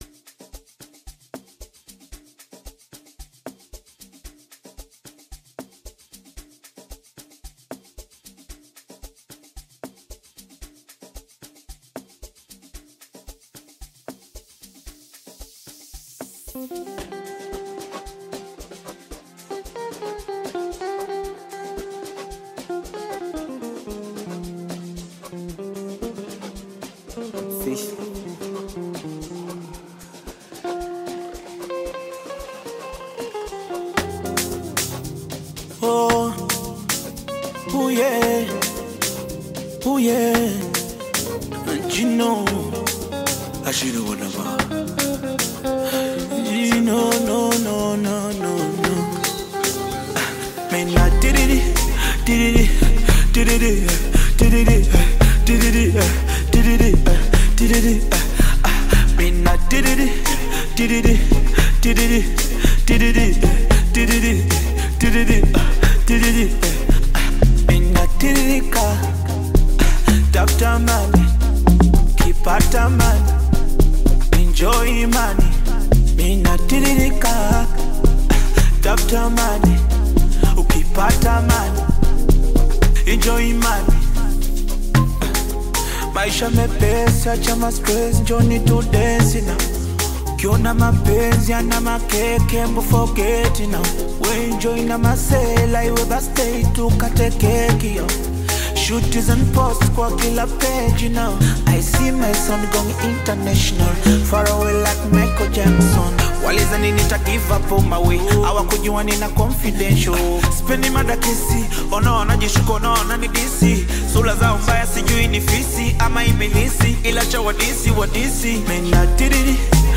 Genre: Amapiano